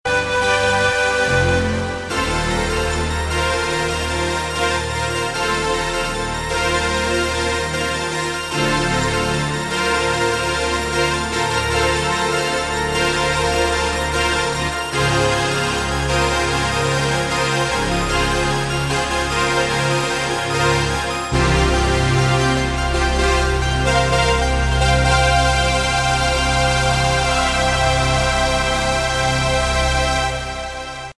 Category: Hard Rock
Bass
Drums
Guitar